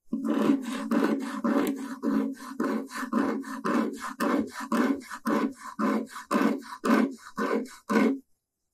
MIDc2w630qq_Serrucho-.wav